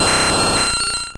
Cri de Ptéra dans Pokémon Or et Argent.